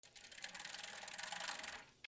自転車に乗る 土の上
/ E｜乗り物 / E-45 ｜自転車